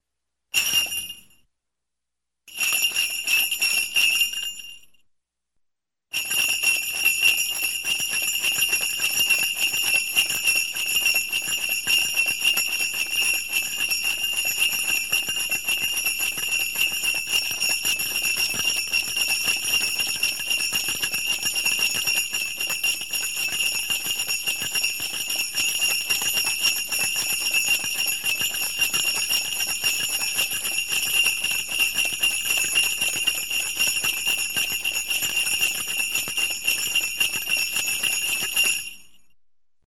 Звук колокольчика, бубенца, перезвон
Библиотека Звуков - Звуки и звуковые эффекты - Колокола, бубенцы